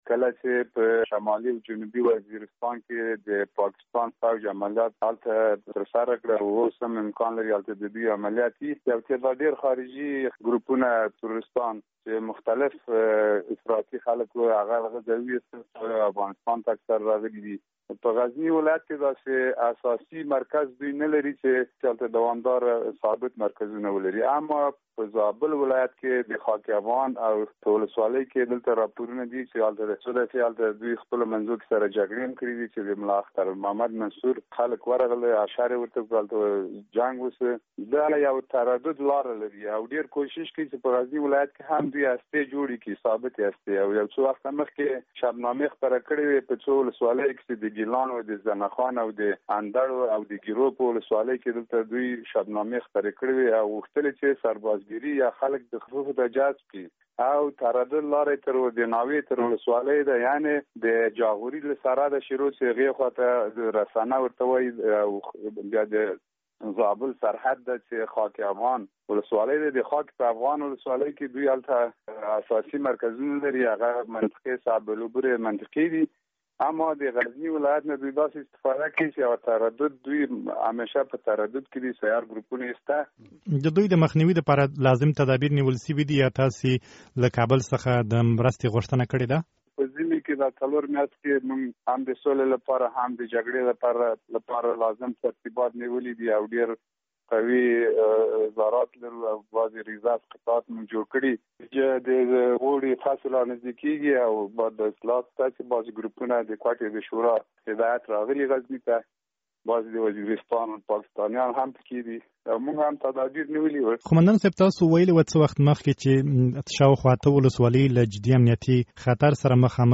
له جنرال امین الله امرخېل سره مرکه